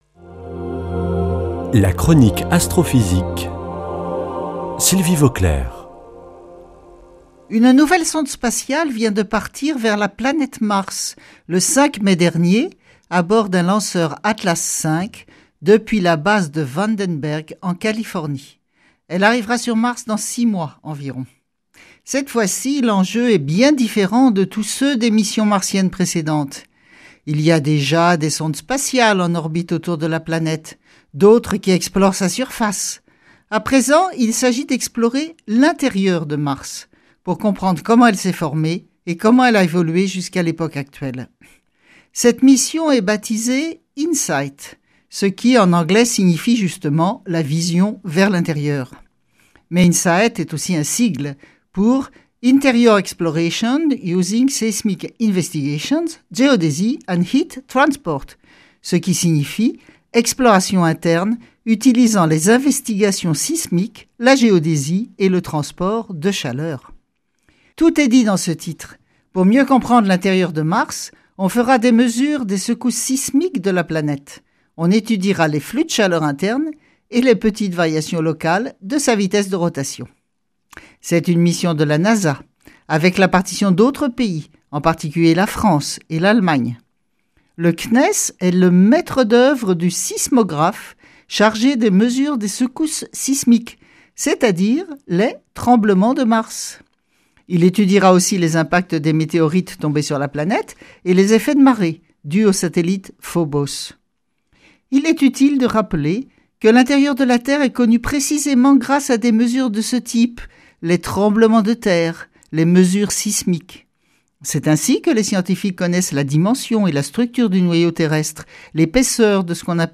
lundi 21 mai 2018 Chronique Astrophysique Durée 3 min